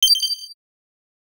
alert_sound_effect.wav